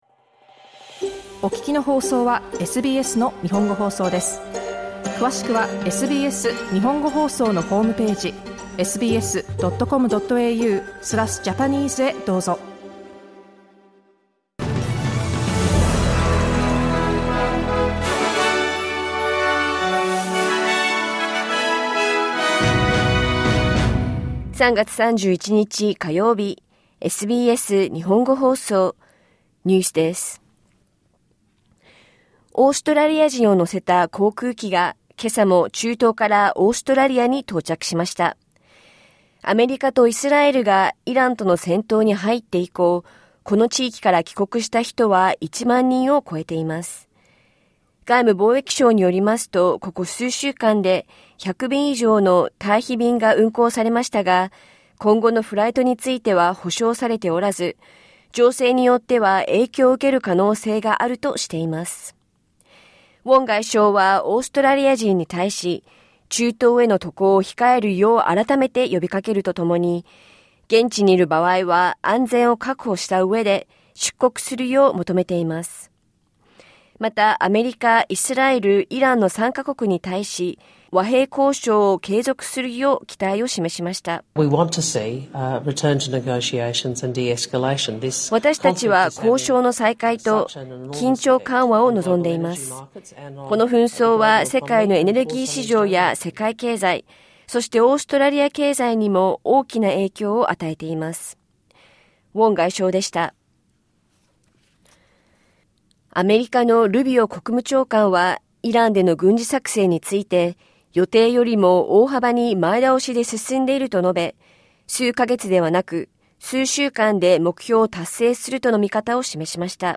SBS Japanese News for Tuesday 31 March